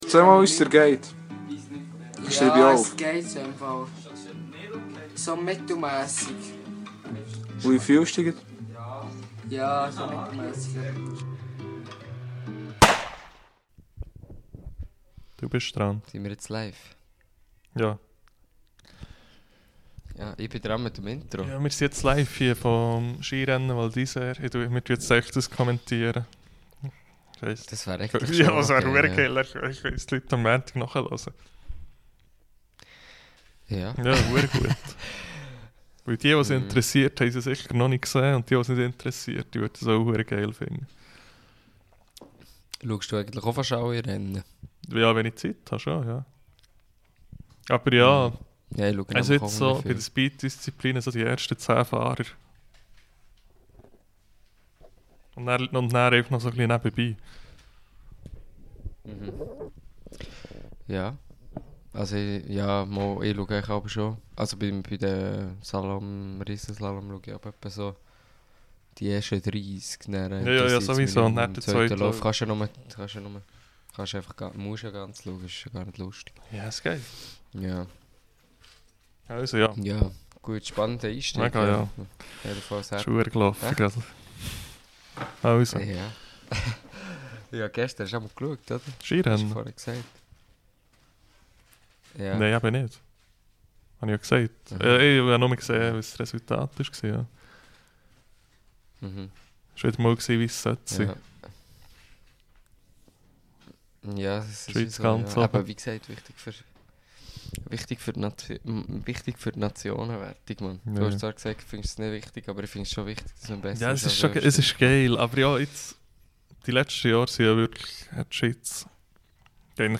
Mir sände quasi live us Val d'Isère. Parallel zu üsere Ufnahm louft nämlech dr 2. Louf vom Slalom und das merkt me dere Foug a paarne Steue chli ah. Näbebi giz ume paar Schwänk us üsere Jugend und üsi Quizzes.